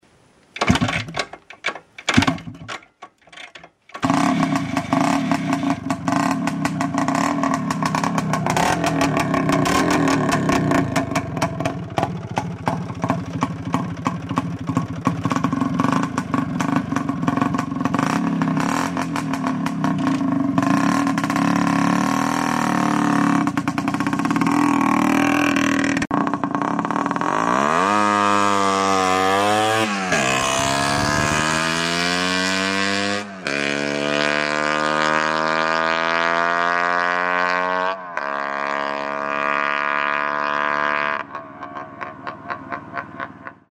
Звуки мотоциклов
ИЖ Планета СПОРТ